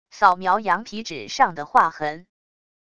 扫描羊皮纸上的划痕wav音频